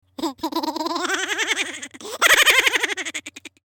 Download Laughter sound effect for free.
Laughter